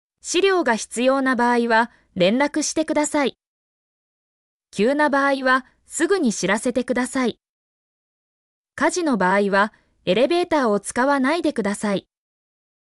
mp3-output-ttsfreedotcom-14_6pgmQdsU.mp3